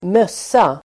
Uttal: [²m'ös:a]